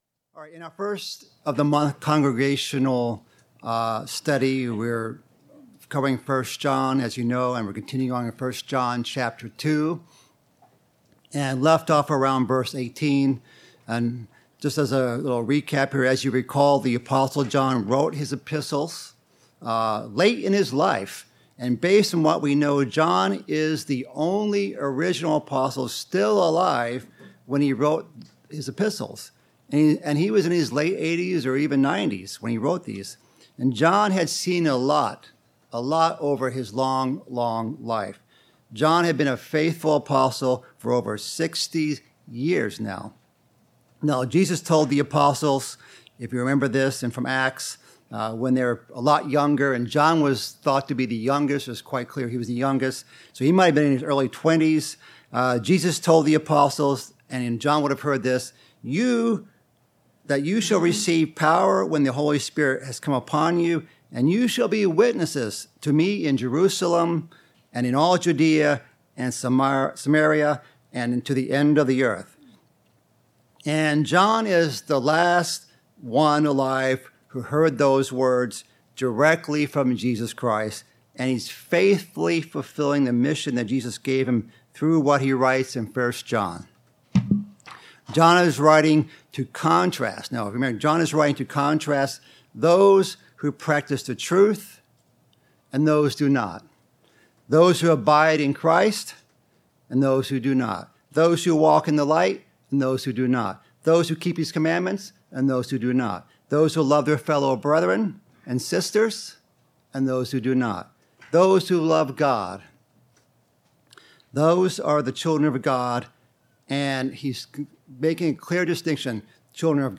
Bible Study: 1 John